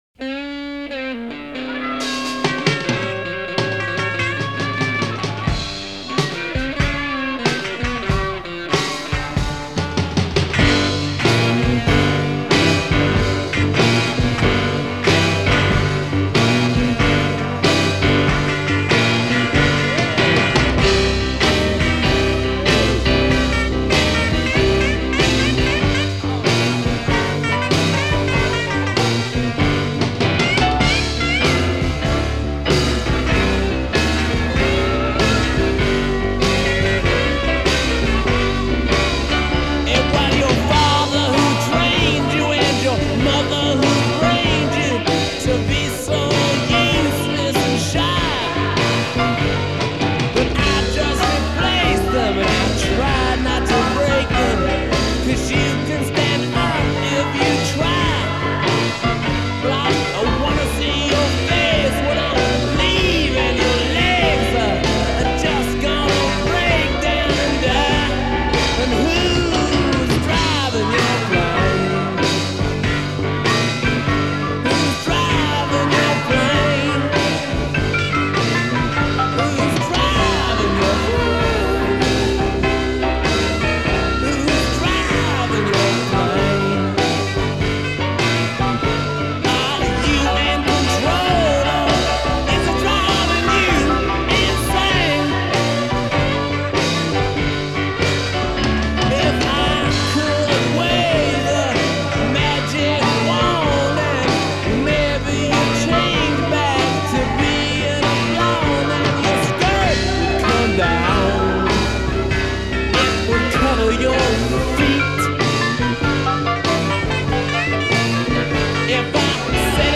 a psychedelic barrelhouse blues number